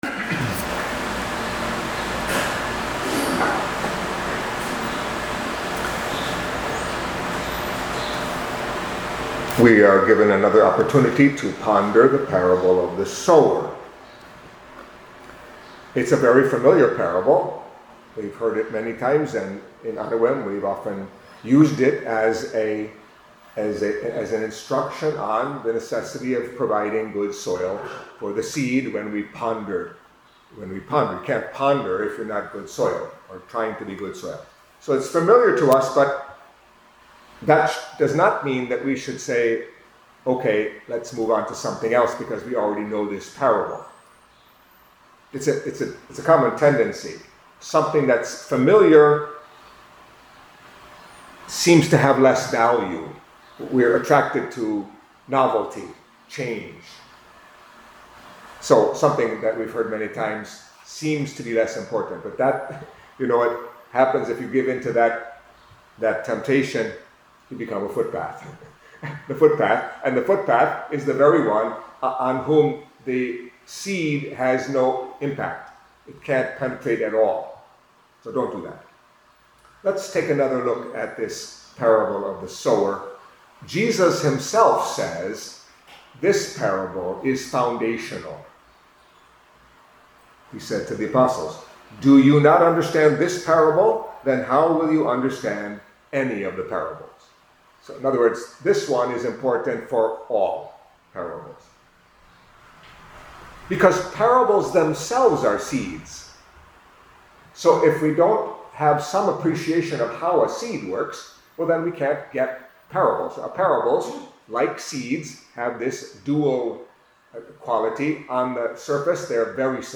Catholic Mass homily for Wednesday of the Third Week in Ordinary Time